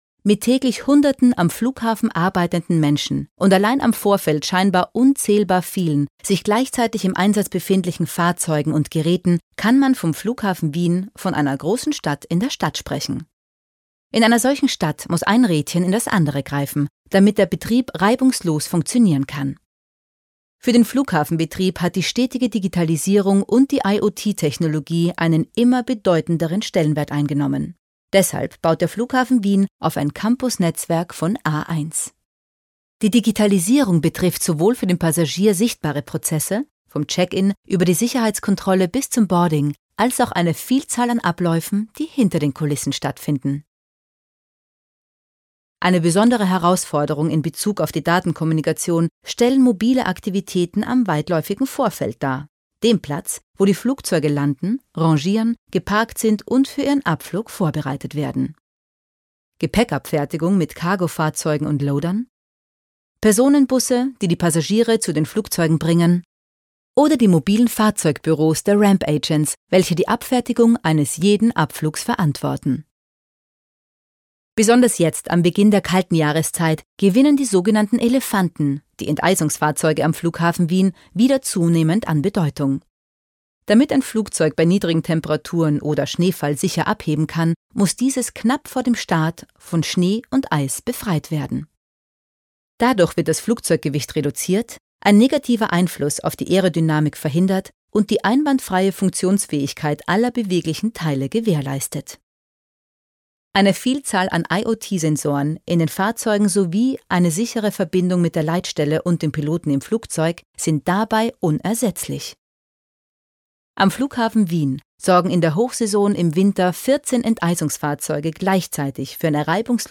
Agentur Sprecherdatei - wir vermitteln Franziska Weisz Sprecherin, Werbesprecherin, Schauspielerin
Erklärvideo
Telefonansage